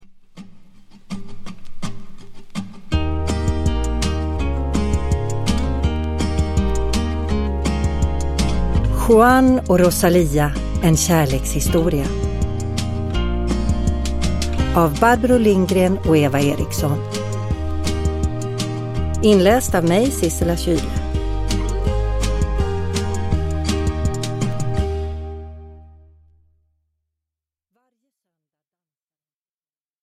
Juan och Rosalia : en kärlekshistoria – Ljudbok – Laddas ner
Uppläsare: Sissela Kyle